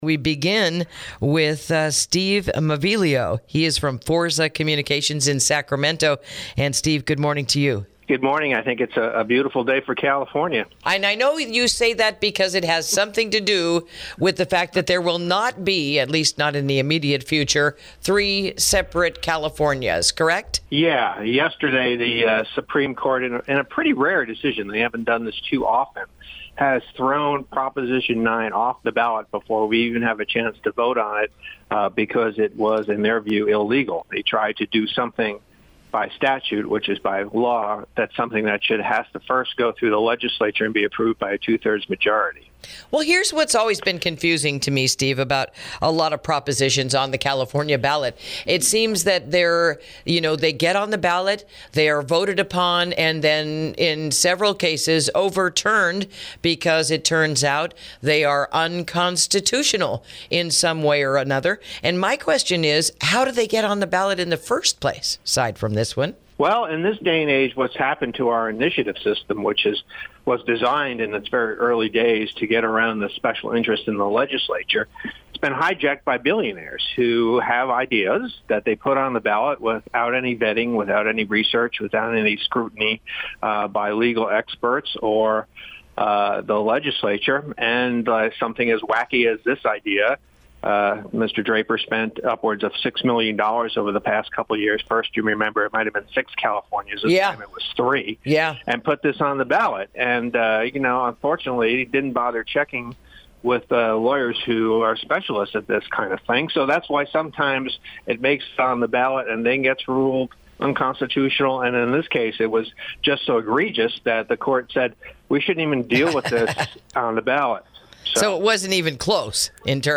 Interview: Why Proposition 9, the California “Three States” Initiative, Will Not Be on the November Ballot